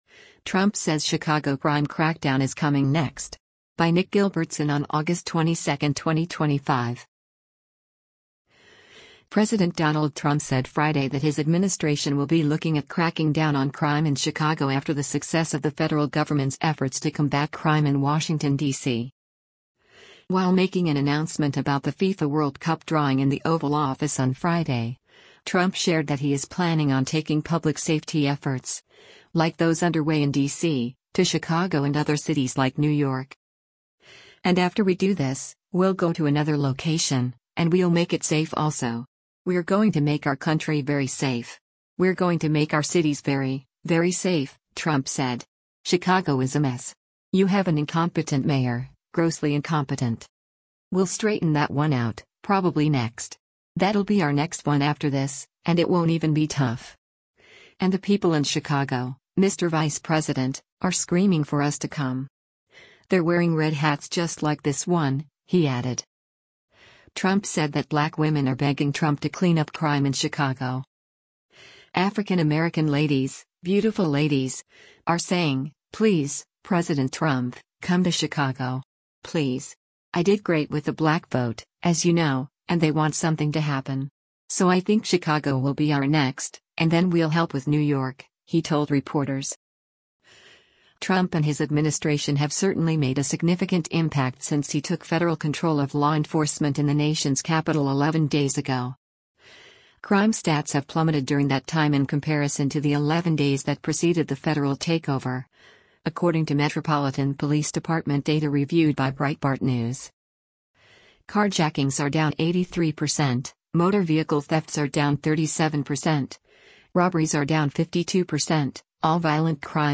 While making an announcement about the FIFA World Cup drawing in the Oval Office on Friday, Trump shared that he is planning on taking public safety efforts, like those underway in D.C., to Chicago and other cities like New York.